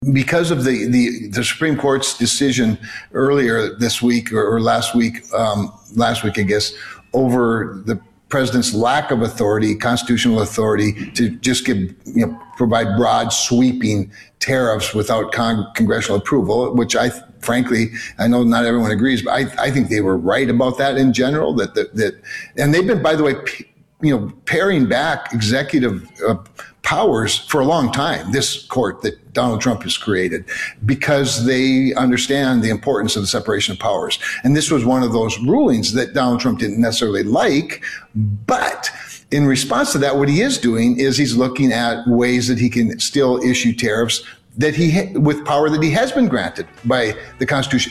WASHINGTON – Republican North Dakota Senator Kevin Cramer said on The Flag he agrees with the Supreme Court’s ruling that struck down President Trump’s tariffs he imposed on nearly every country using an emergency powers law.